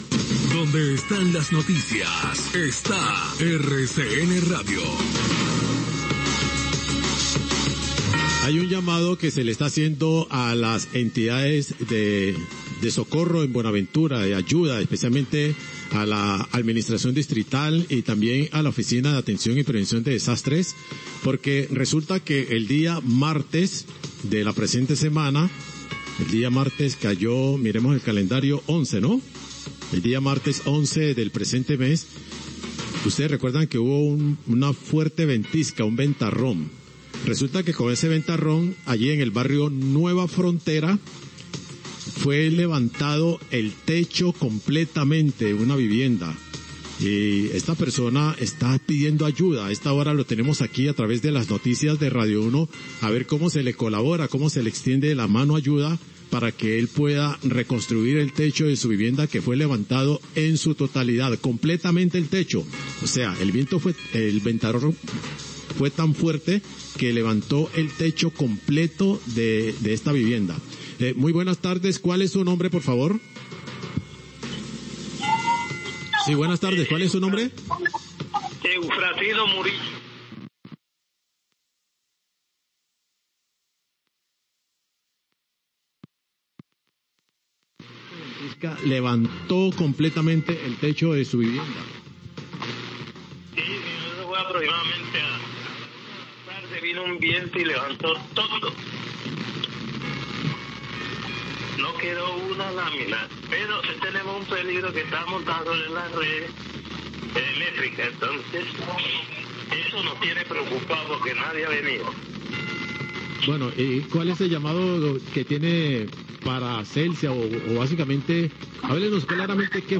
Oyente hace llamado a Celsia por techo de vivienda sobre cableado,1238pm